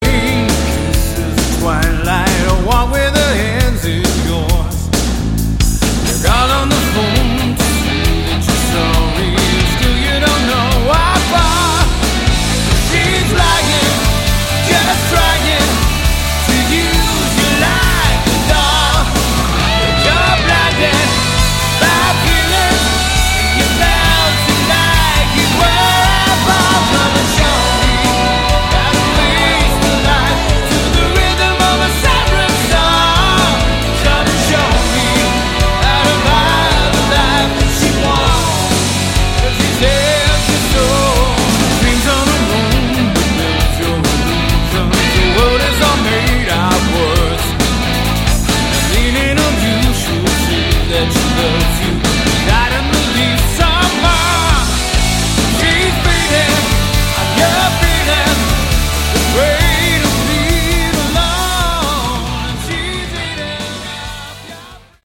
Category: AOR/Melodic Rock
It's simply good, straight ahead rock.